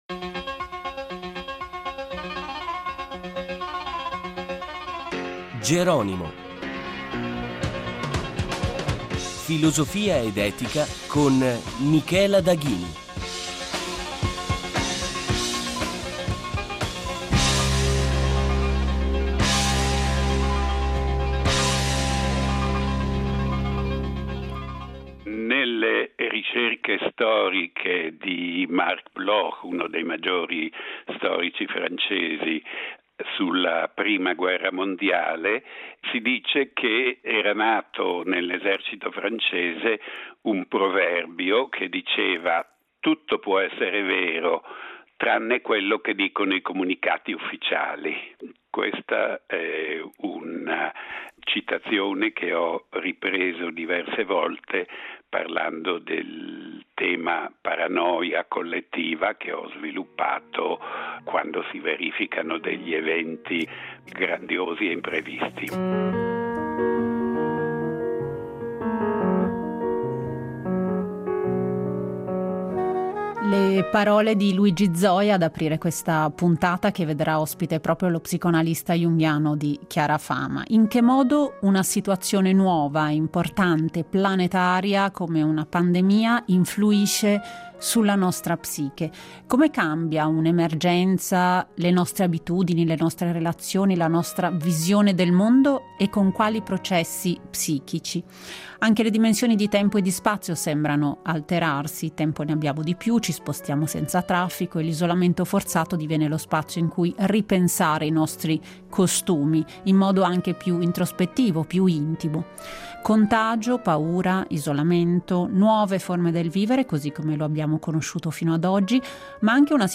Con il noto psicoanalista, una riflessione per aiutarci a comprendere da diverse angolazioni il disagio psichico, esistenziale e sociale della nostra epoca. Il contagio della psiche - Intervista itegrale Tra i libri dell’autore ricordiamo il fortunato libro “Paranoia.